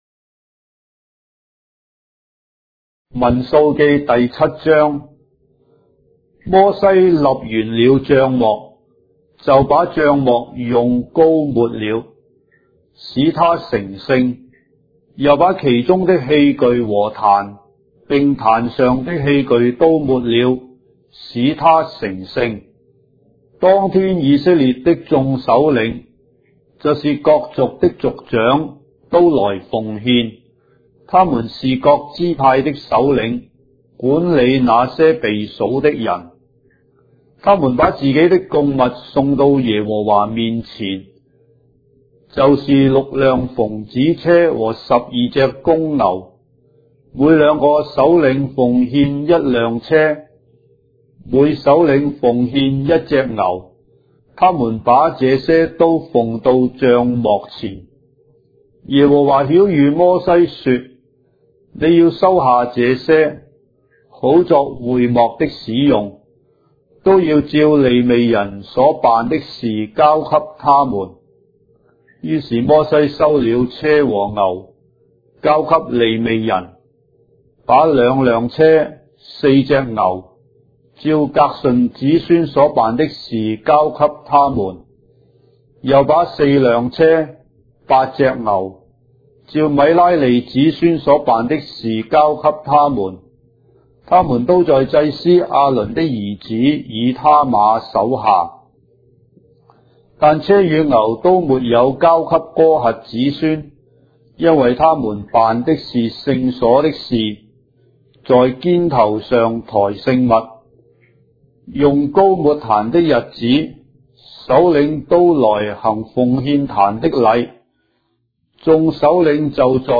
章的聖經在中國的語言，音頻旁白- Numbers, chapter 7 of the Holy Bible in Traditional Chinese